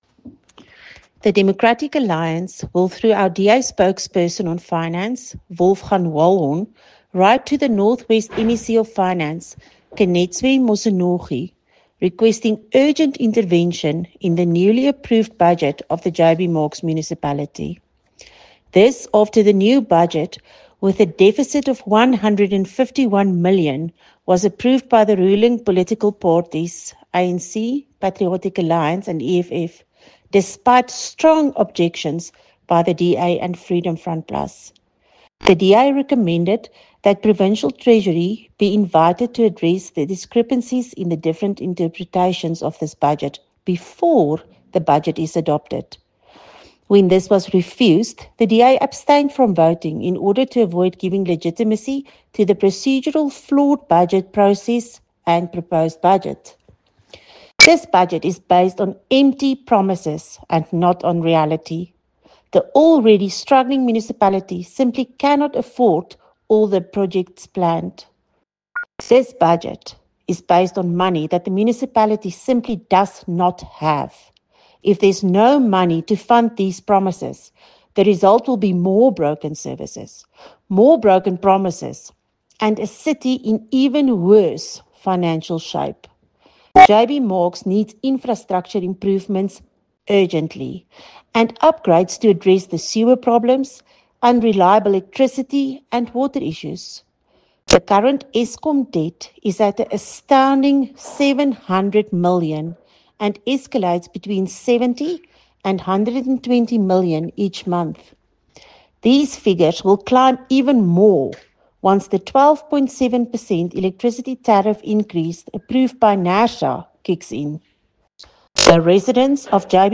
Note to Broadcasters: Please find attached soundbites in